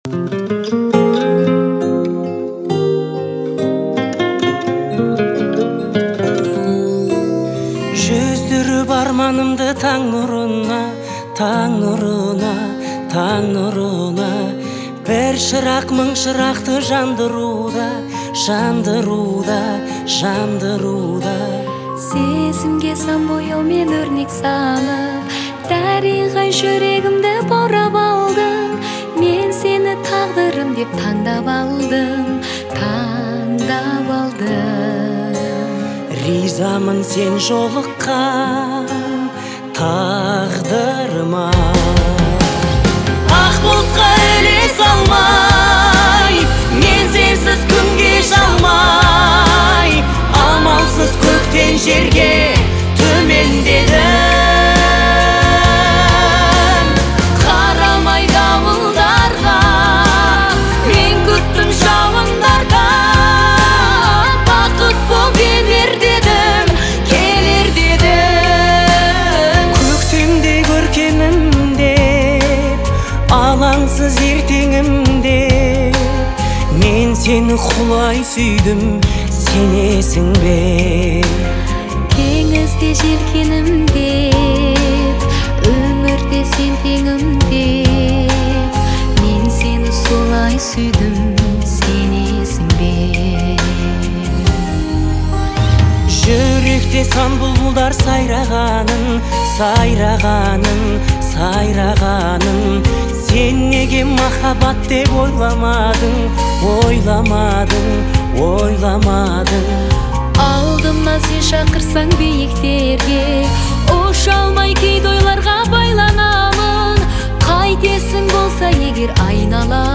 это романтическая песня в жанре поп